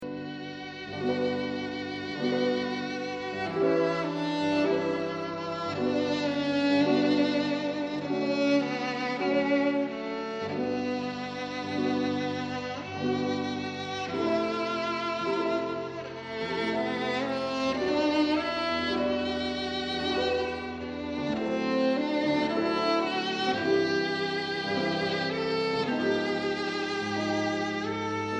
Violin
in concert and in studio